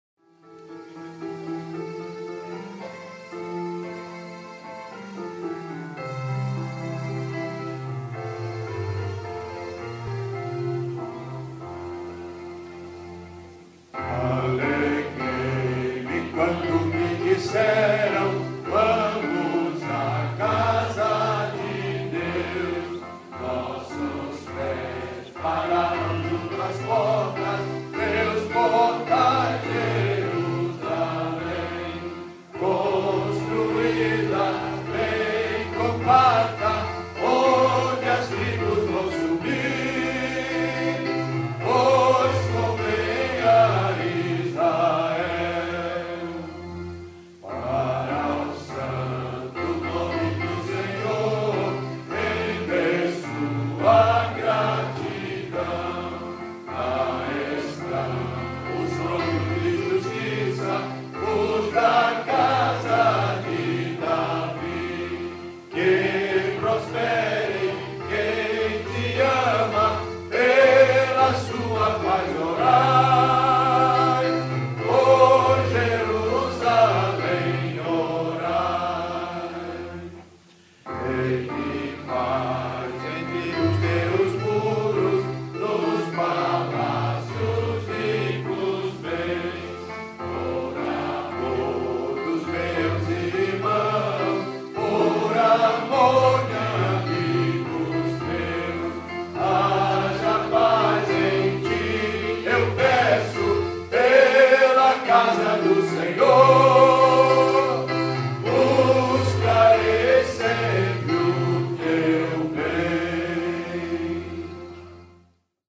salmo_122B_cantado.mp3